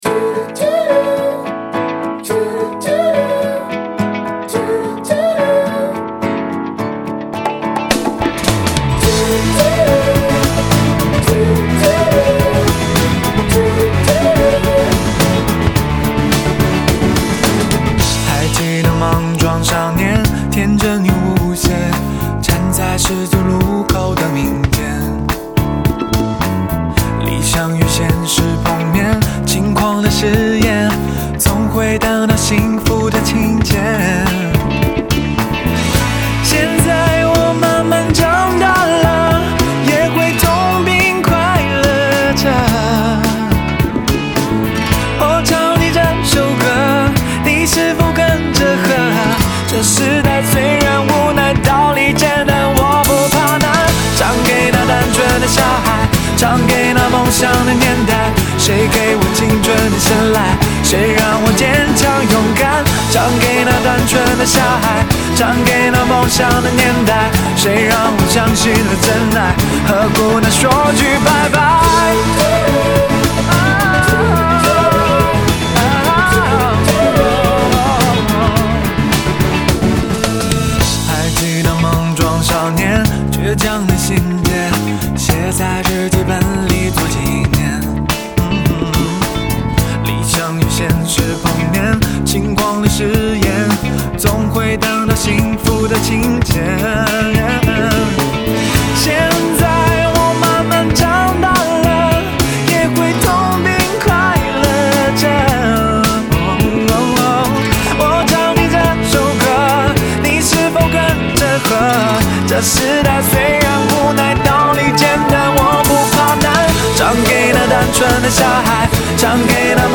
[流行音乐]